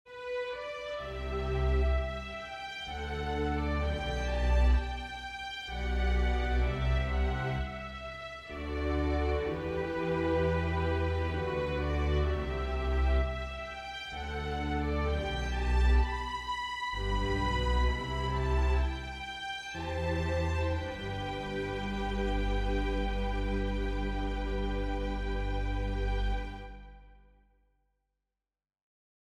03-Familia-de-CUERDAS-Los-Planetas-Jupiter-Holst.mp3